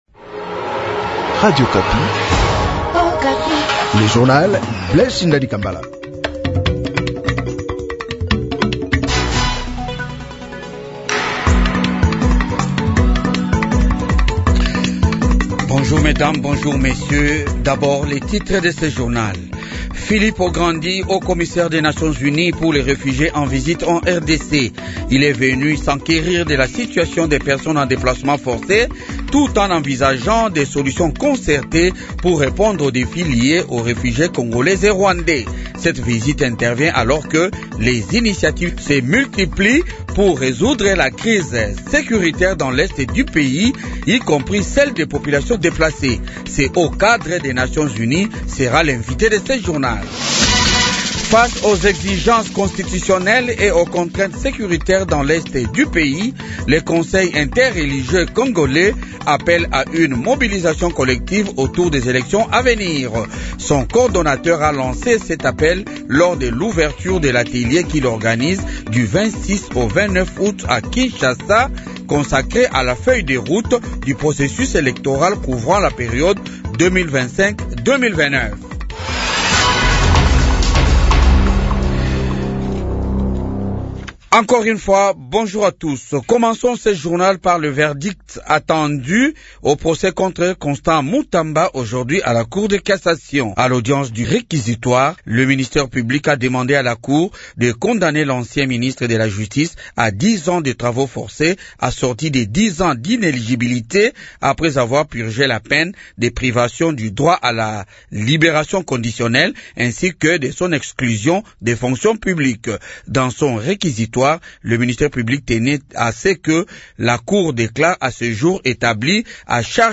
Journal Francais matin 8H
-Invité : Filippo Grandi, chef du HCR en visite en RDC